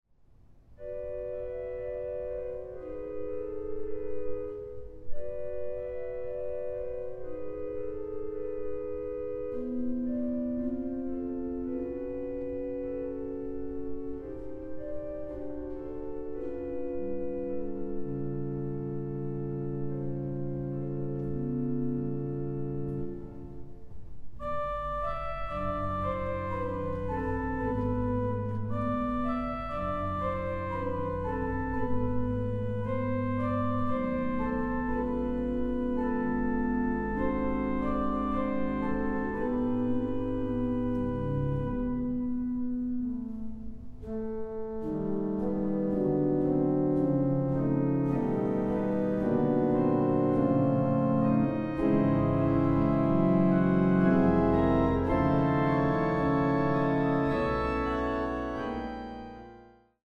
Organ
Percussion
Gong
Recording: Het Orgelpark, Amsterdam, 2023